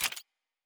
pgs/Assets/Audio/Sci-Fi Sounds/Weapons/Weapon 07 Foley 3.wav
Weapon 07 Foley 3.wav